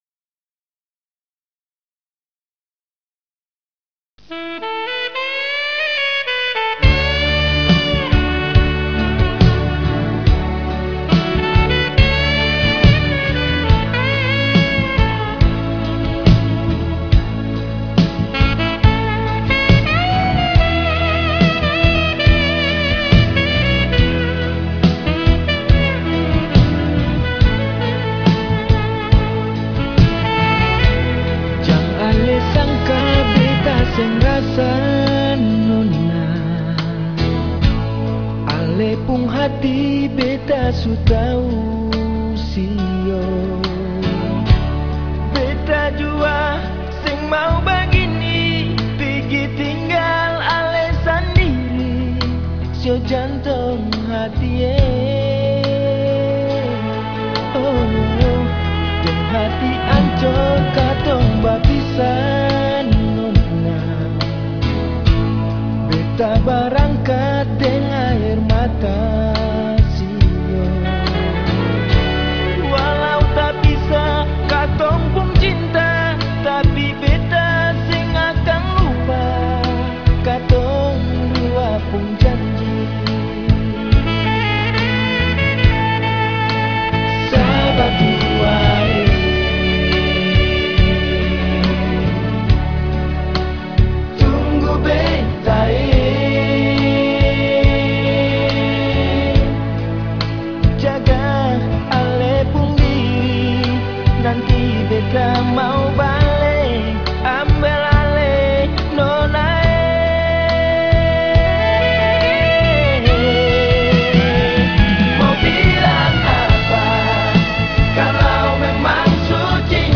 karya seni musik daerah Maluku